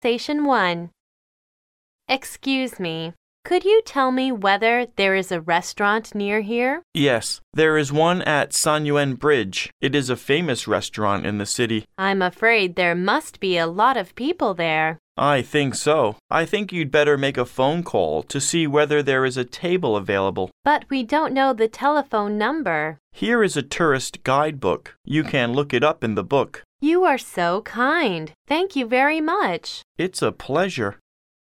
Conversation 1